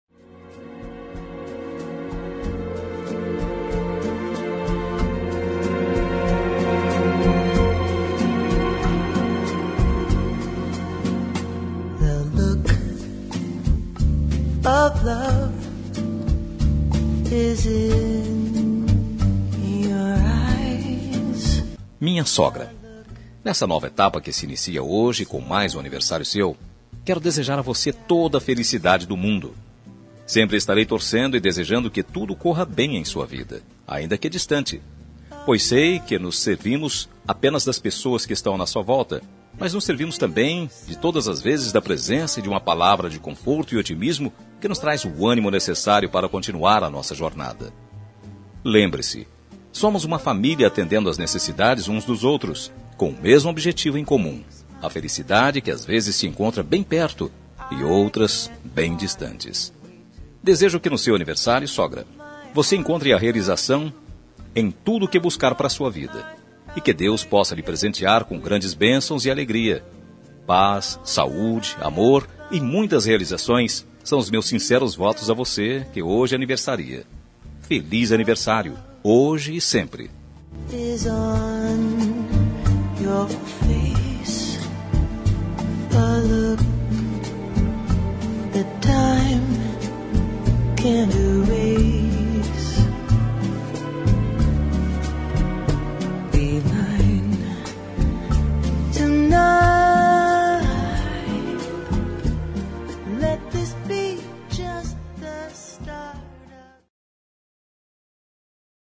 Telemensagem Aniversário de Sogra – Voz Masculina – Cód: 1982 – Distante